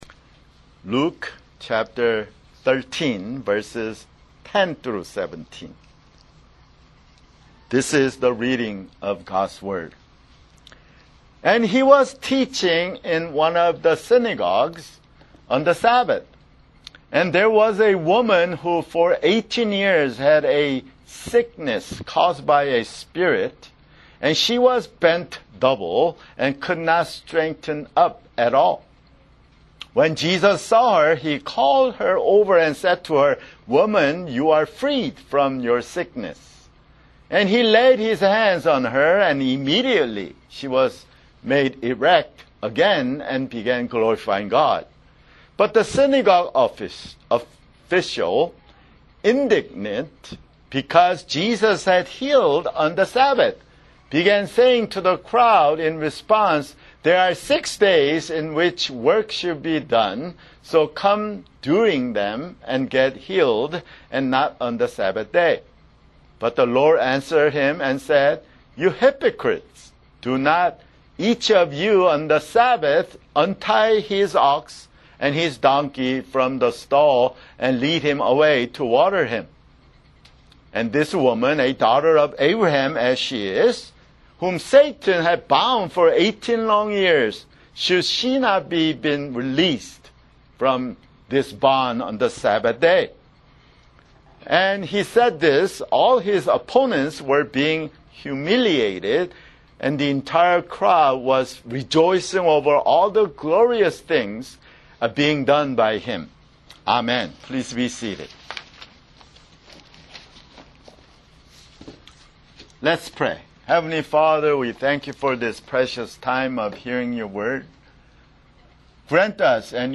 [Sermon] Luke (95)